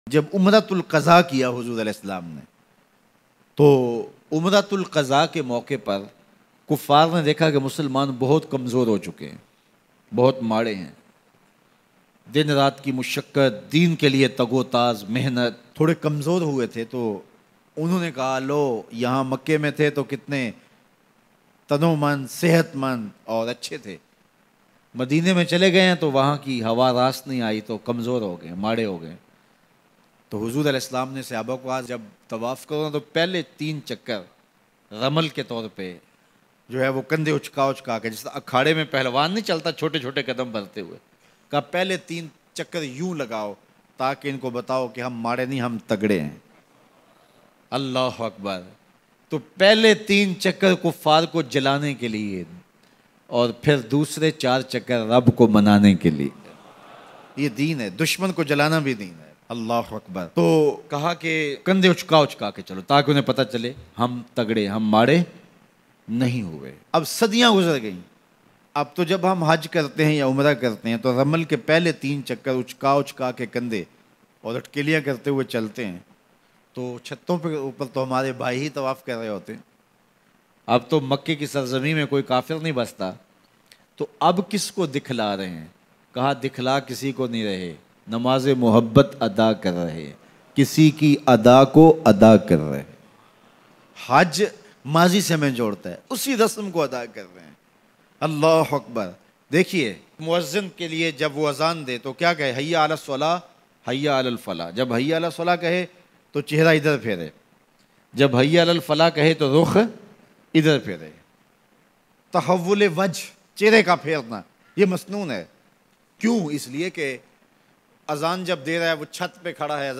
Pehalwano ki trah Twaf krne ka Hukm Bayan